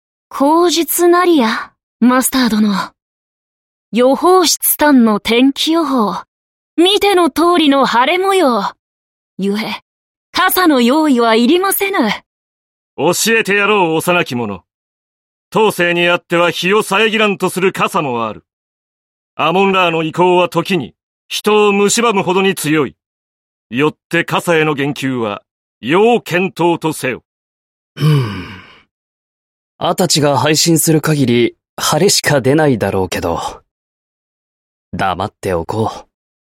声优 阿部里果&子安武人&小野贤章